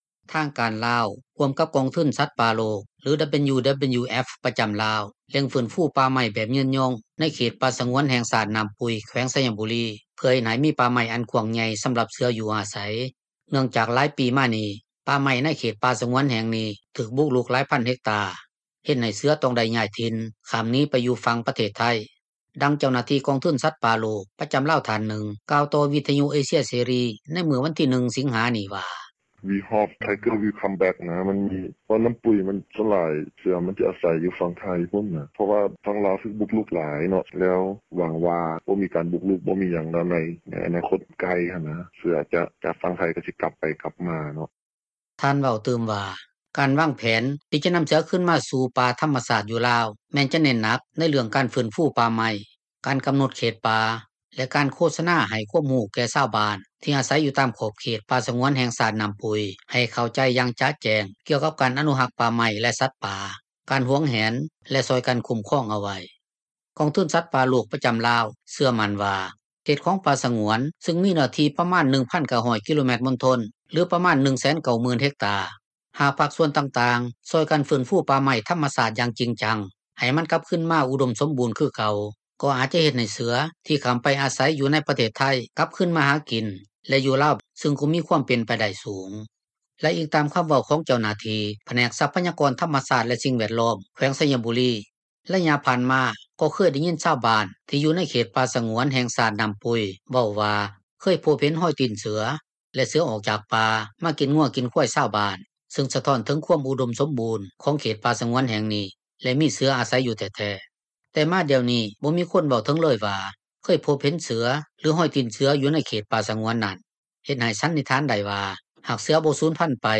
ດັ່ງເຈົ້າໜ້າທີ່ກອງທຶນສັດປ່່າໂລກ ປະຈໍາລາວທ່ານນຶ່ງ ກ່າວຕໍ່ວິທຍຸເອເຊັຽເສຣີ ໃນມື້ວັນທີ 1 ສິງຫານີ້ວ່າ: